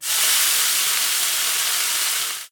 Cauterization.ogg